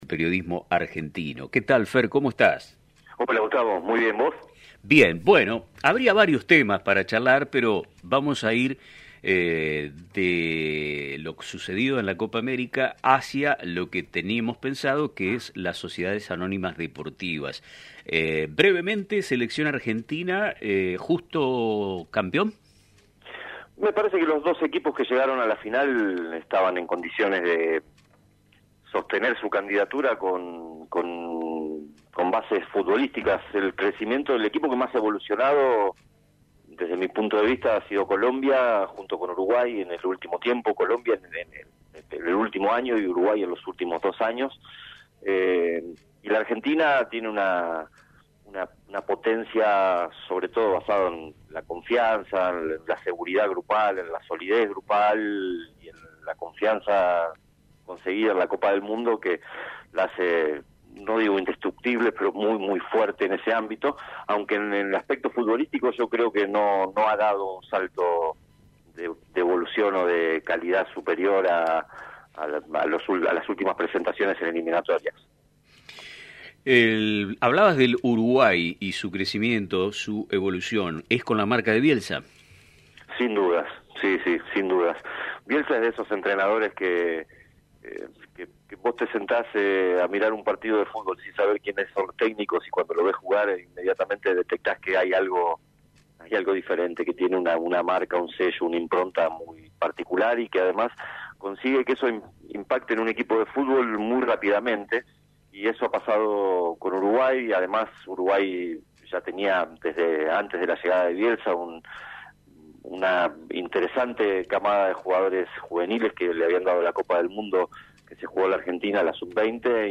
En una entrevista exclusiva para el programa de radio «Nuestro Tiempo» que se transmite por LT 35 Radio Mon Pergamino